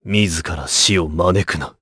Clause_ice-Vox_Skill5_jp.wav